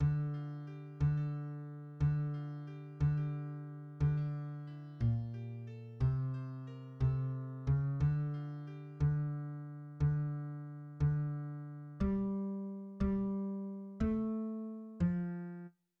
{ << \relative c '' { \new Staff { \set Staff.midiMinimumVolume = #0.1 \set Staff.midiMaximumVolume = #0.3 \set Score.currentBarNumber = #39 \bar "" \tempo 4=90 \key f \major \time 6/8 a8 f g a16 a~ a4 a8 f g a16 g~ g4 g16 d8. e8 f g a c c bes a4 r8 a f g } } \new Staff \with {midiInstrument = #"acoustic bass"} { \set Staff.midiMinimumVolume = #0.7 \set Staff.midiMaximumVolume = #0.9 \clef bass \key f \major \time 6/8 d4. d d d d bes, c c4 cis8 d4. d d d g g a f4 r8 } >> } \midi{}